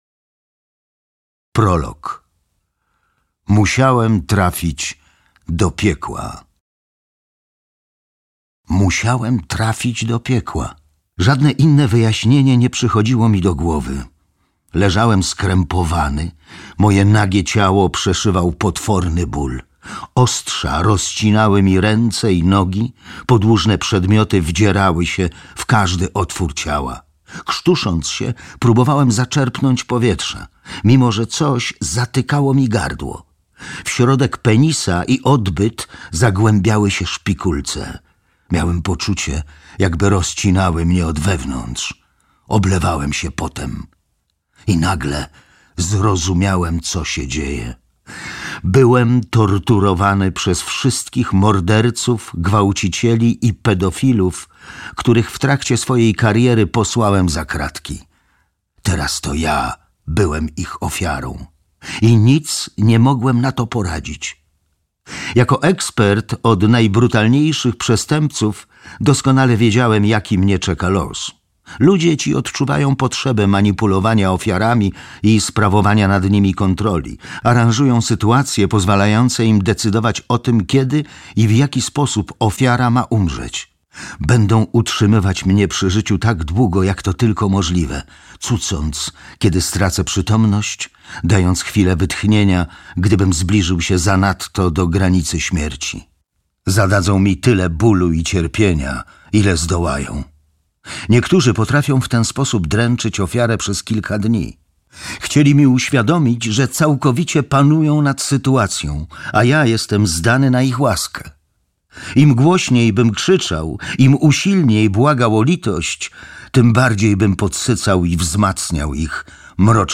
Mindhunter. Tajemnice elitarnej jednostki FBI zajmującej się ściganiem seyjnych przestępców - John Douglas, Mark Olshaker - audiobook